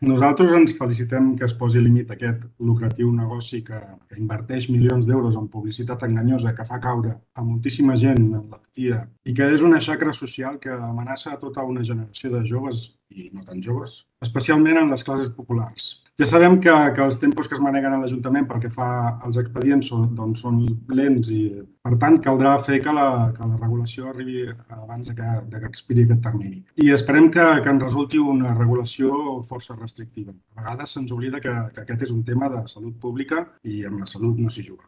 Tal com expressava el portaveu d’En Comú Podem,
Isaac Martínez, tot els grups es mostraven partidaris de “posar límits al lucratiu negoci de les apostes i el joc, perquè el joc és una xacra social i un atemptat a la salut pública”.